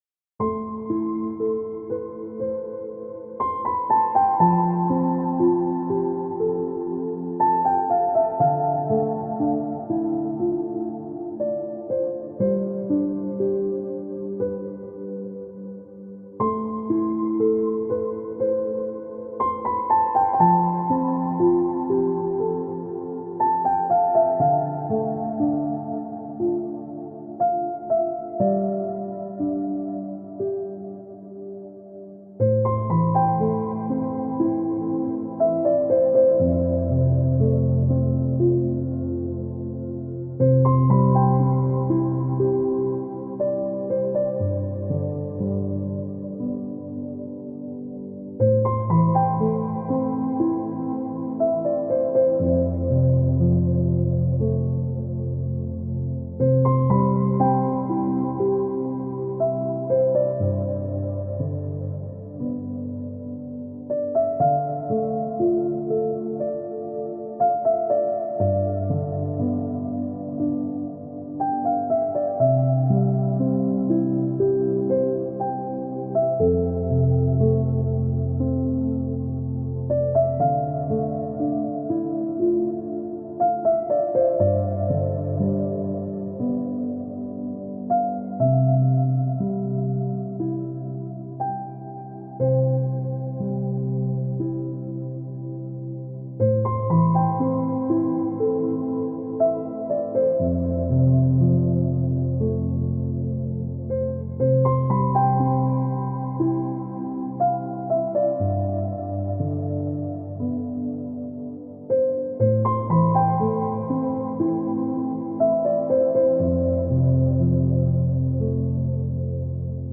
Piano Music.mp3